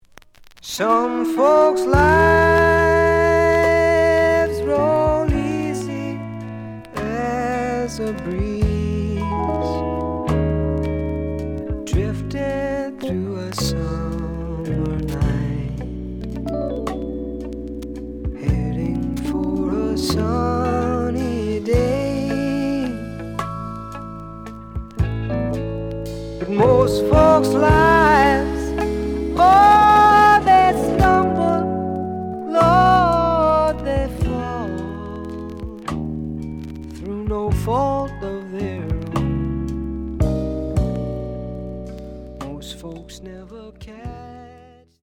The audio sample is recorded from the actual item.
●Genre: Rock / Pop
Some noise on parts of B side.)